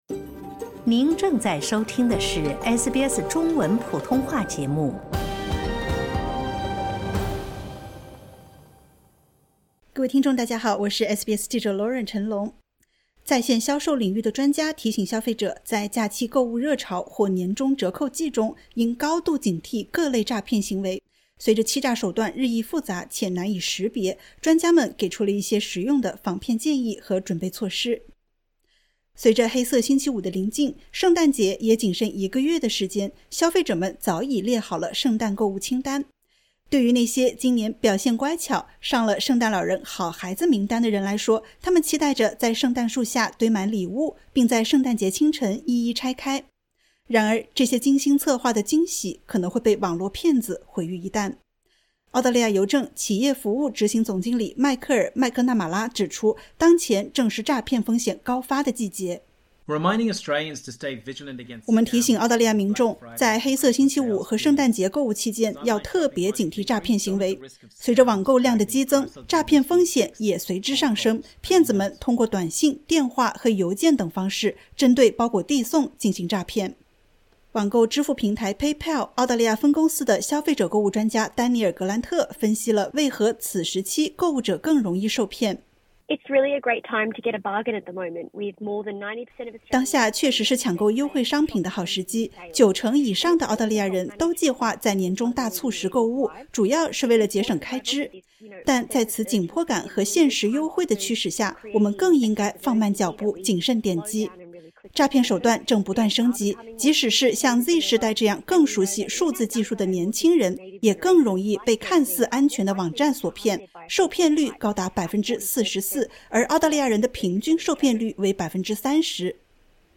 在线销售领域的专家提醒消费者，在假期购物热潮或年终折扣季中，应高度警惕各类诈骗行为。随着欺诈手段日益复杂且难以识别，专家们给出了一些实用的防骗建议和准备措施。点击 ▶ 收听完整报道。